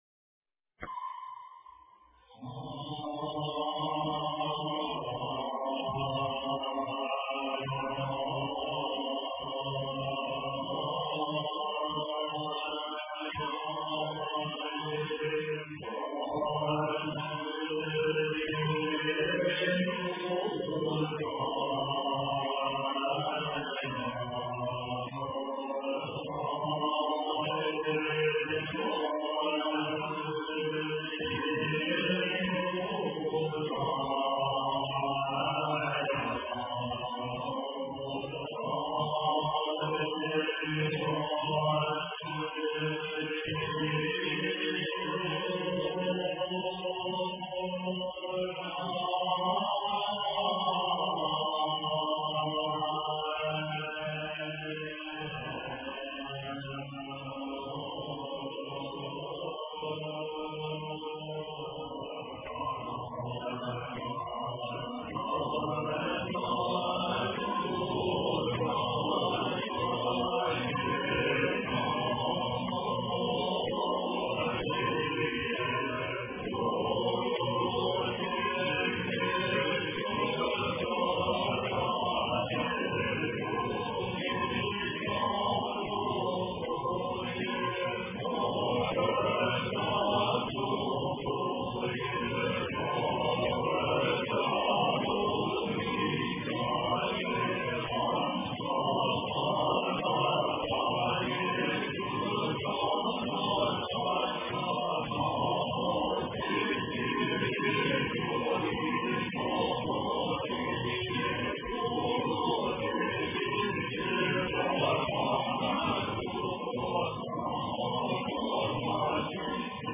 大悲咒 诵经 大悲咒--经忏版 点我： 标签: 佛音 诵经 佛教音乐 返回列表 上一篇： 大悲咒 下一篇： 大悲咒(童音版) 相关文章 大自然音乐（溪川）--未知 大自然音乐（溪川）--未知...